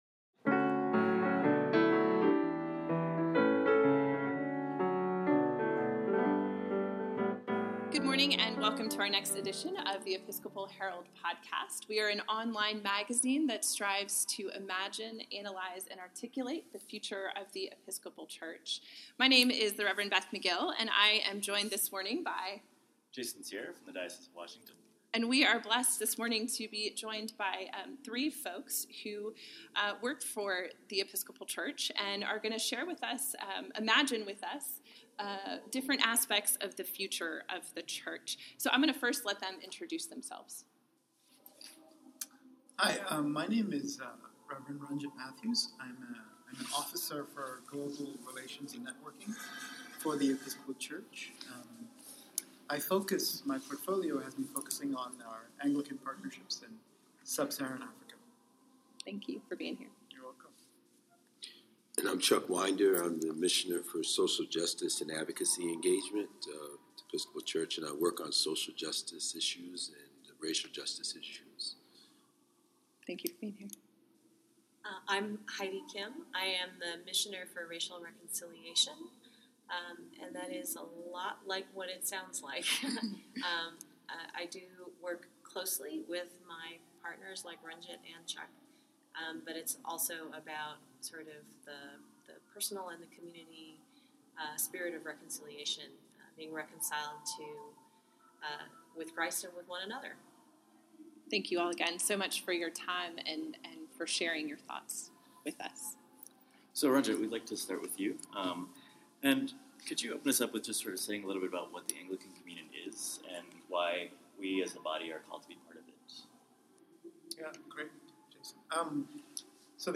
Episcopal Herald Podcast – Conversation Series – DFMS Staff